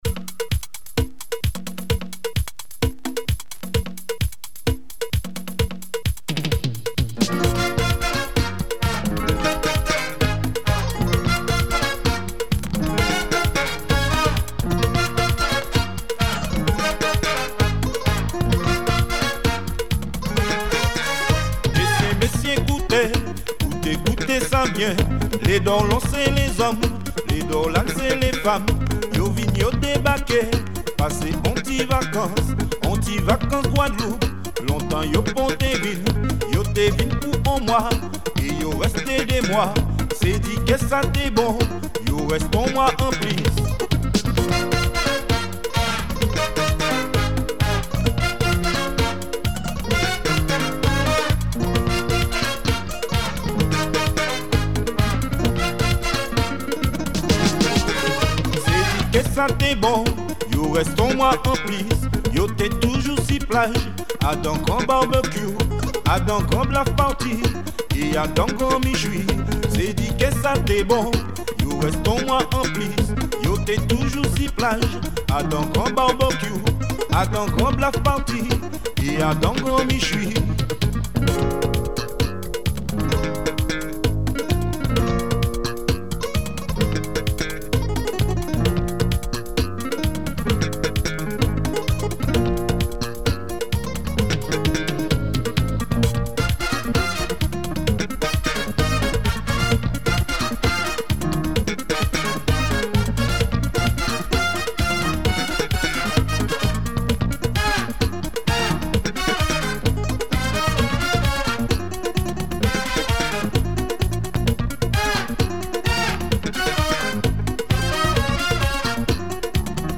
synth
sax
guitar… Overall very good production
be it zouk funk or latin cuban style.